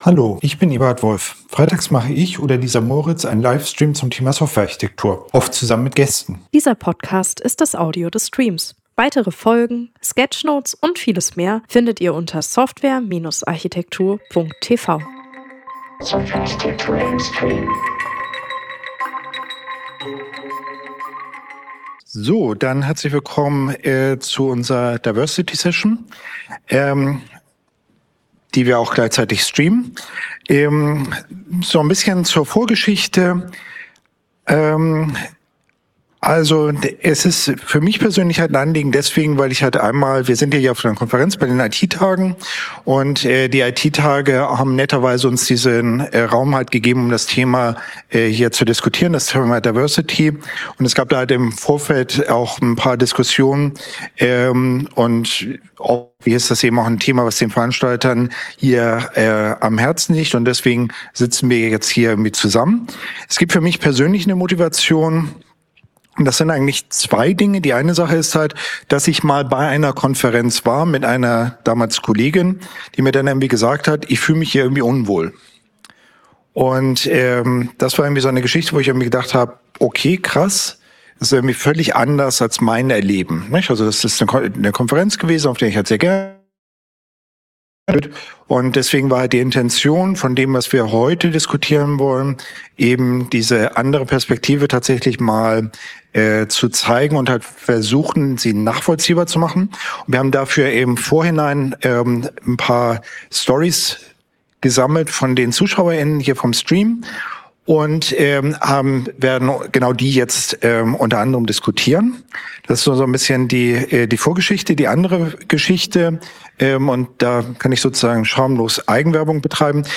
Stimmen aus der Praxis – Live-Stream mit Erfahrungsberichten und Diskussion
Wie erleben Menschen, die in der IT unterrepräsentiert sind, ihren Arbeitsalltag wirklich? In diesem Live-Stream bringen wir ungefilterte Stimmen direkt auf die Bühne der IT-Tage.